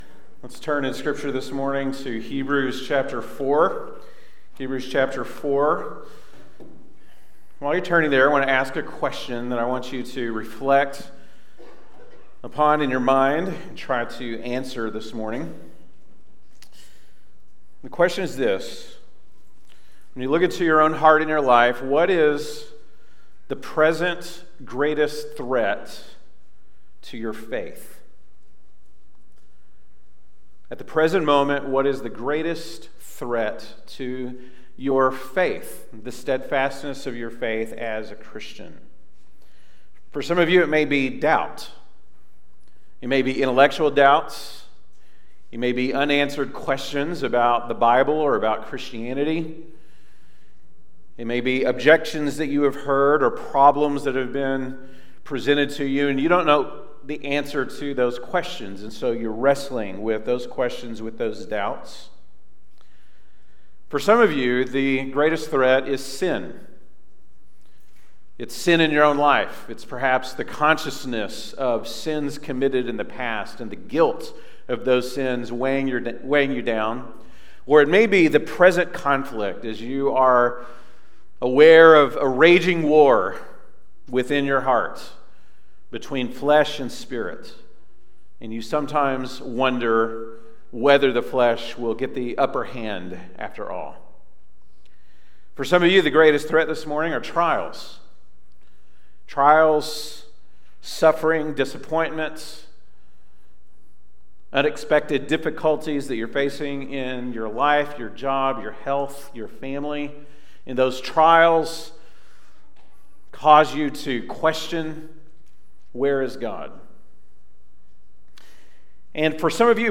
November 19, 2023 (Sunday Morning)